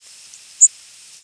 Yellow Warbler diurnal flight calls
Bird in flight.